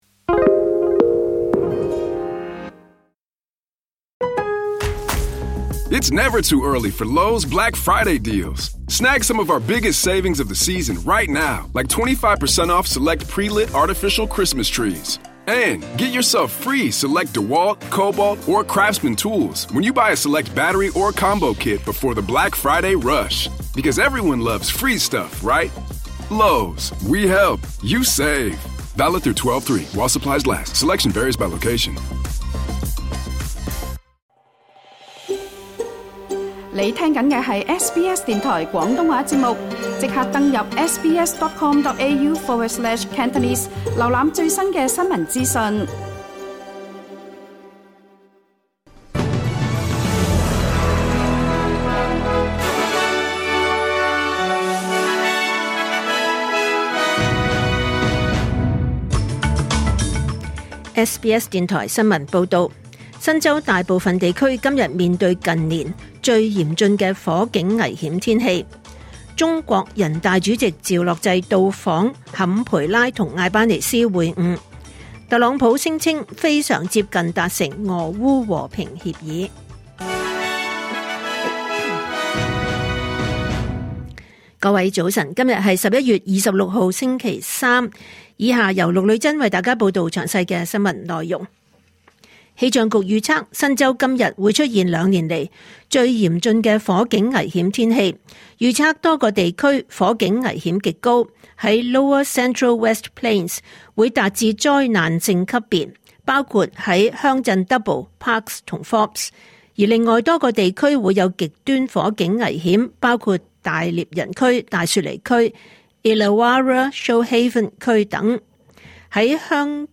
2025年11月26日SBS廣東話節目九點半新聞報道。